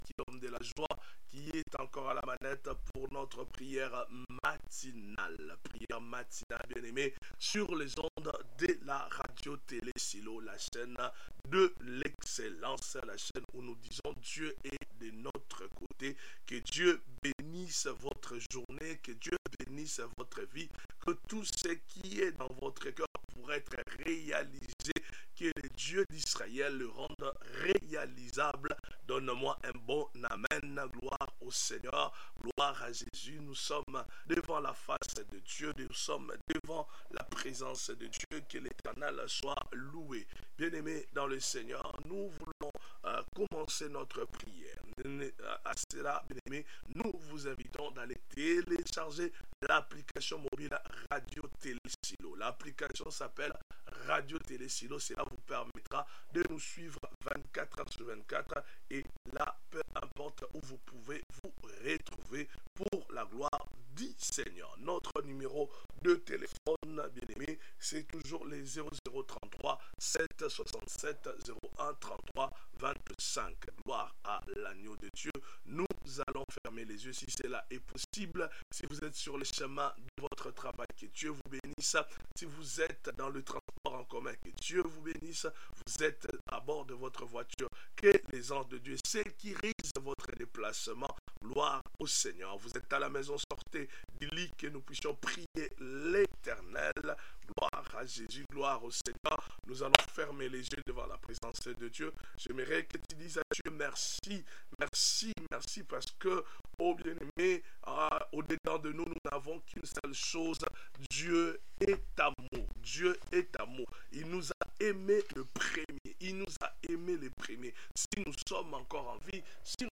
Sa grâce m'est suffit, Prière matinale